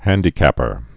(hăndē-kăpər)